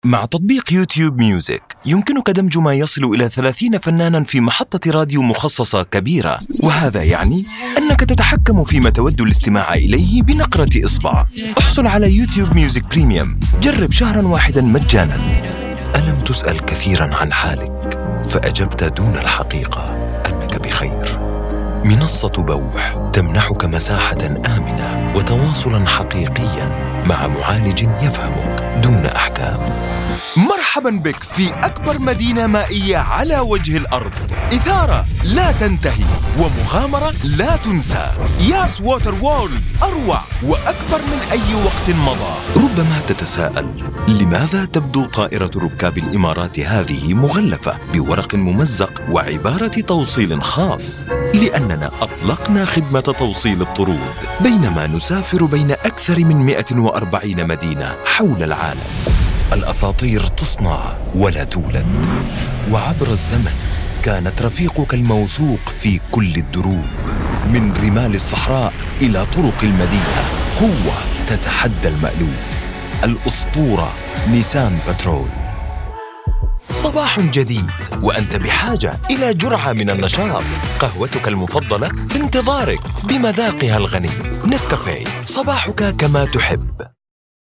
Male
Yng Adult (18-29), Adult (30-50)
Modern Standard Arabic & Native Egyptian.
Conversational, Engaging, Warm, Deep, & Authoritative tone for Commercials, Corporate, Promos, explainer videos, E-Learning, IVR, and more.
Main Demo
Arabic Msa Commercial Vo Demo